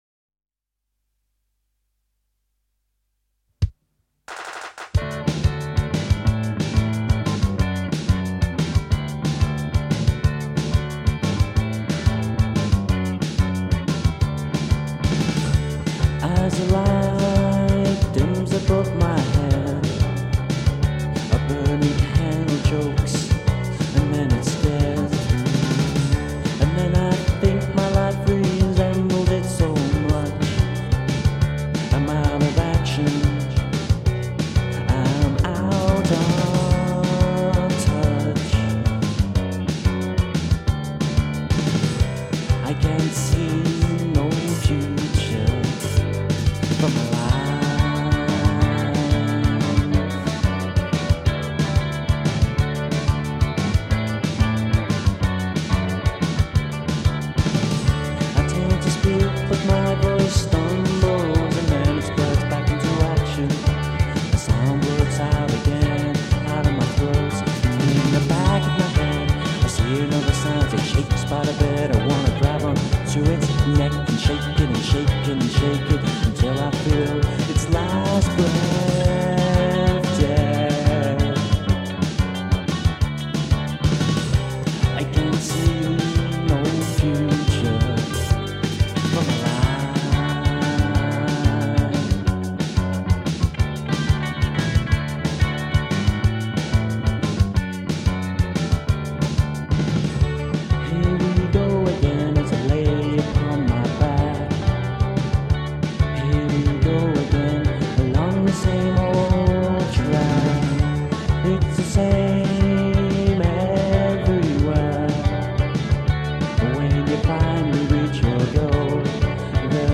Their recordings were done in Macclesfield.